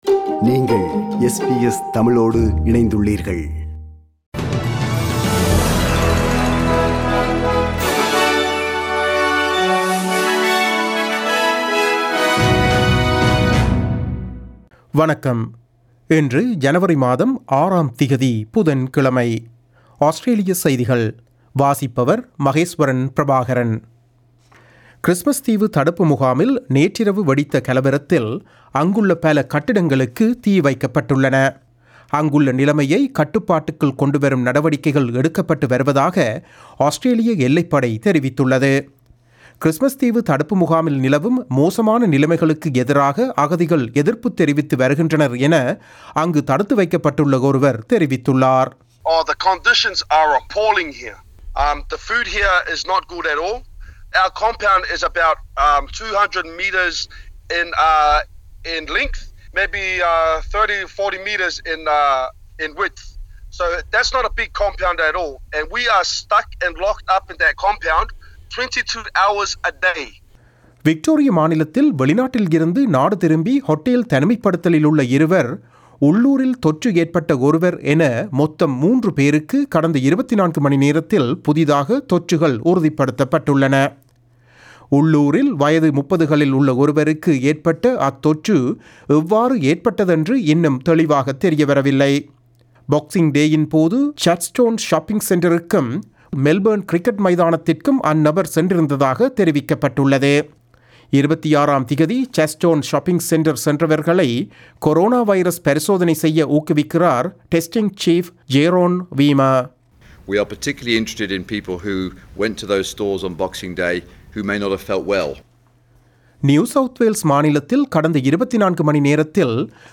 Australian news bulletin for Wednesday 06 January 2021.